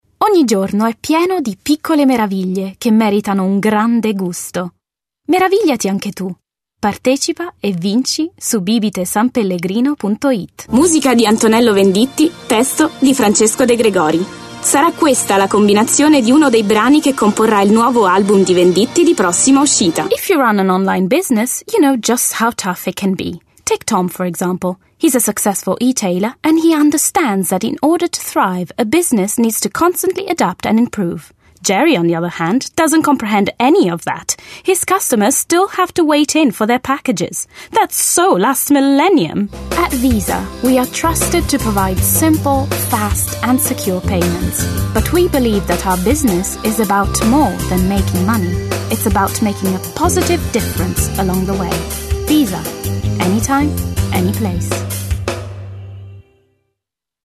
Showreel
Female / 20s, 30s / Italian Showreel http